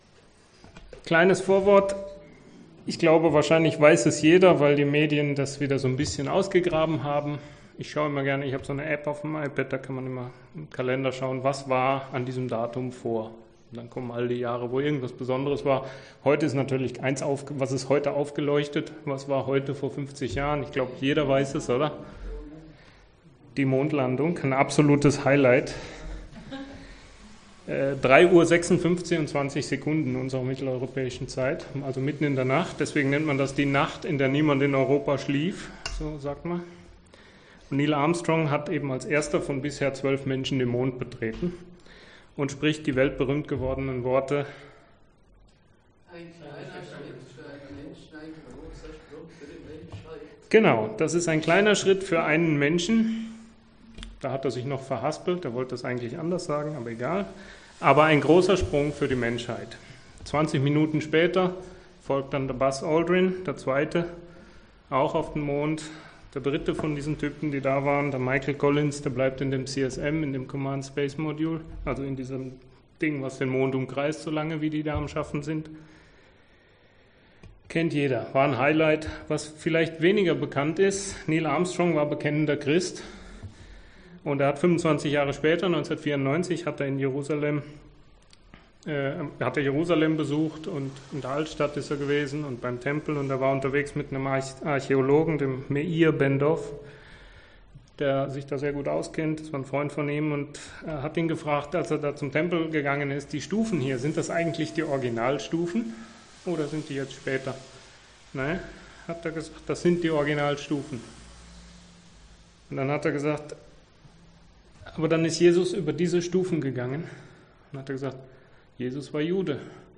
2019 in Gastpredigt 1089 LISTEN https